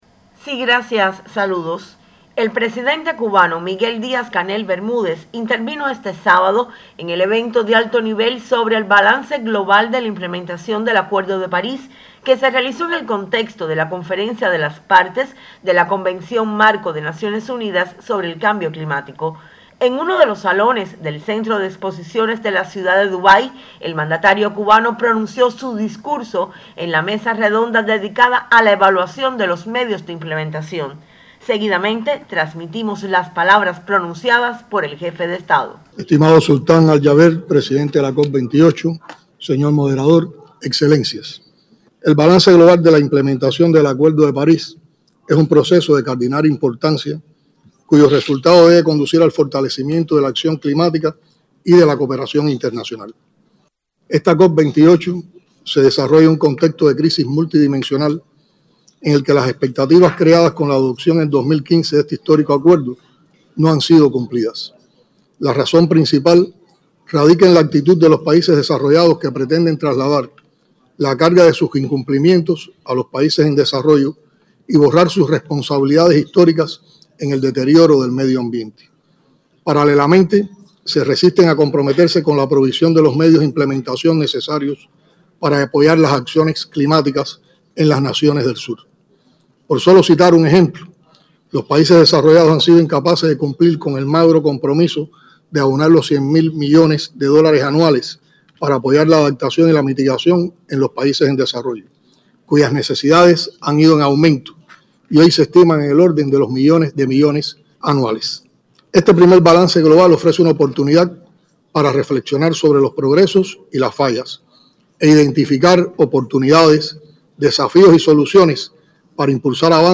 El Primer Secretario del Comité Central del Partido Comunista y Presidente de la República de Cuba, Miguel Díaz-Canel Bermúdez, compartió en la mañana de este sábado, en Dubái, un breve y esclarecedor discurso durante el evento de alto nivel sobre el Balance Global de la implementación del Acuerdo de París -evento desarrollado en el contexto de la 28va Conferencia de las Partes de la Convención Marco de Naciones Unidas sobre Cambio Climático (COP28).
dc__discurso_de_balance_global.mp3